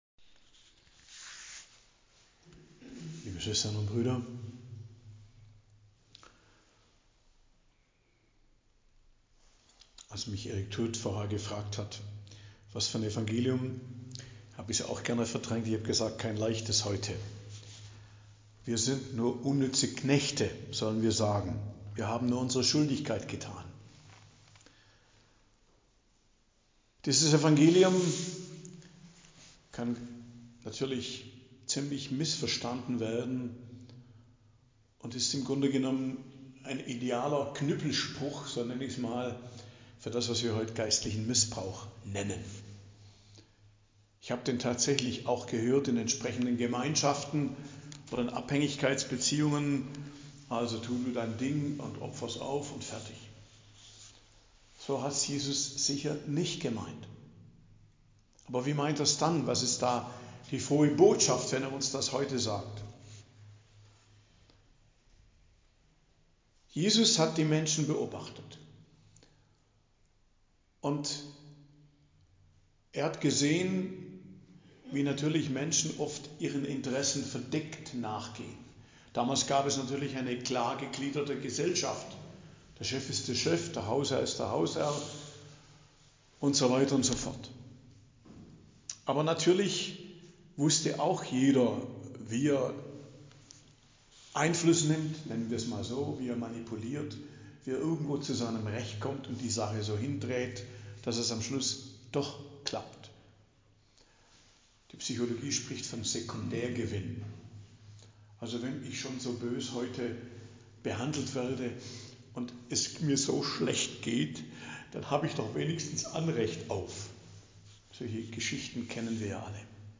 Predigt am Dienstag der 32. Woche i.J. 12.11.2024 ~ Geistliches Zentrum Kloster Heiligkreuztal Podcast